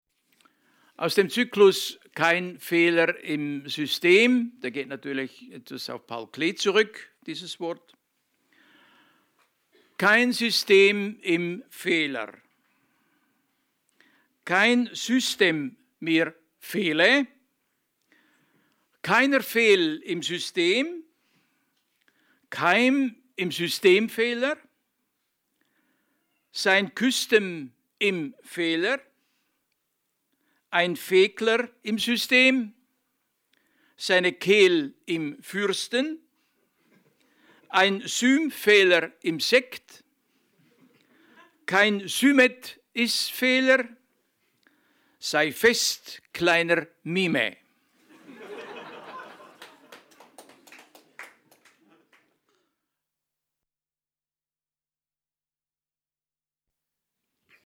Lesung von Eugen Gomringer in der literaturWERKstatt Berlin zur Sommernacht der Lyrik – Gedichte von heute